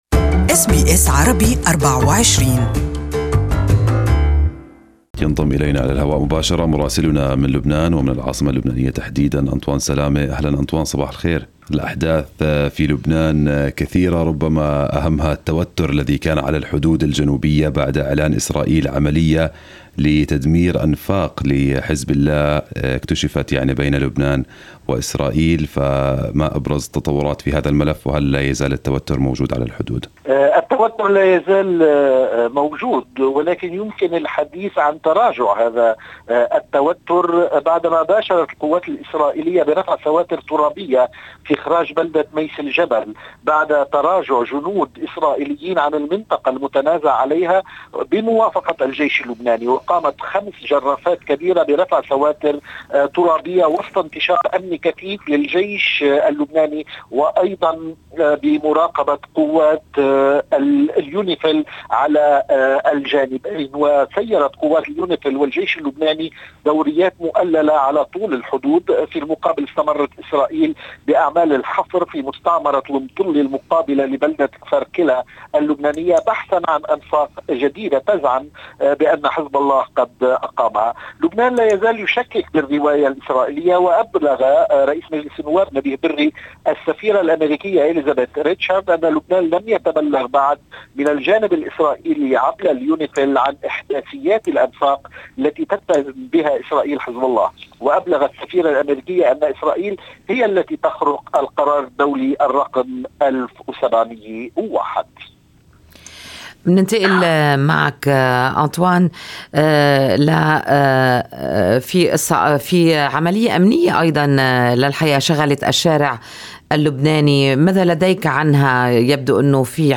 Listen to the full report from our correspondent in Arabic above